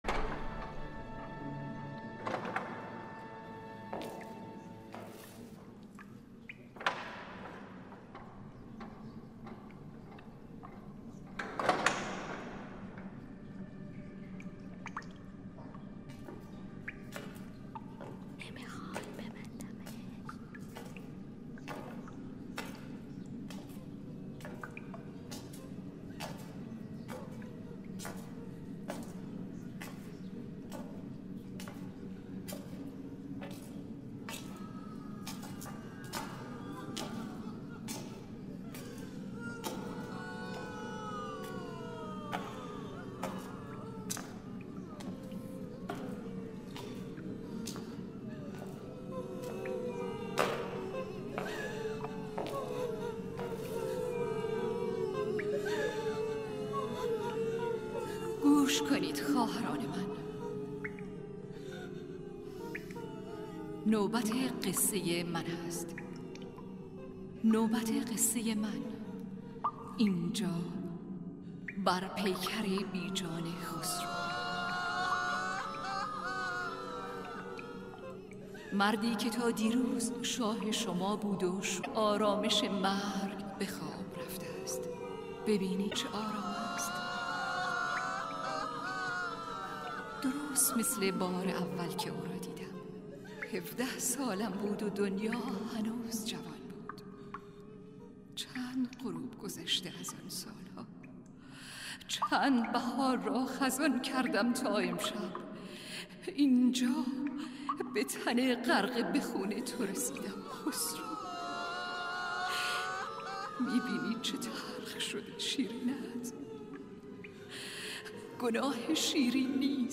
شیرین عنوان فیلمی ایرانی ساخته عباس کیارستمی است که در سال ۱۳۸۷ خورشیدی (۲۰۰۸ میلادی) تولید شد. فیلم تصویرگر نگاه ۱۱۳ بازیگر زن، بدون هیچ سخنی به دوربین فیلمبرداری است، در حالی که صداهایی از قرائت منظومه خسرو و شیرین نظامی گنجوی به گوش می‌رسد.